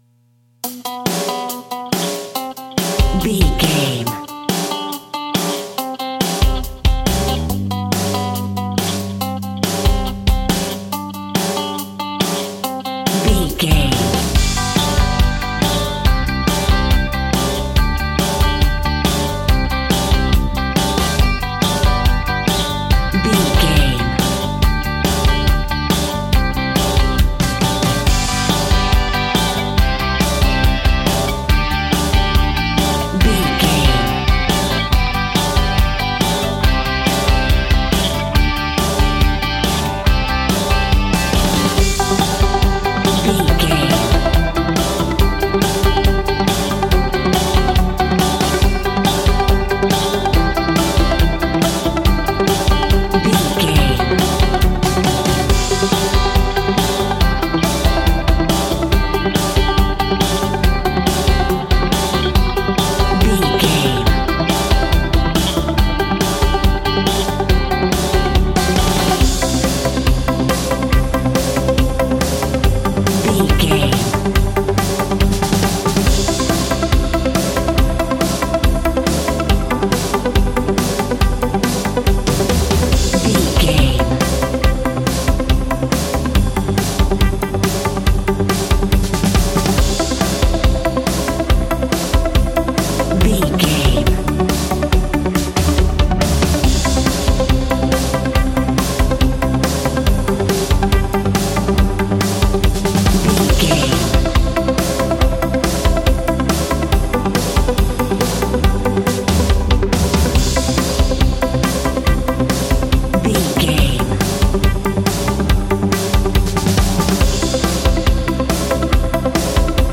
Ionian/Major
groovy
powerful
organ
drums
bass guitar
electric guitar
piano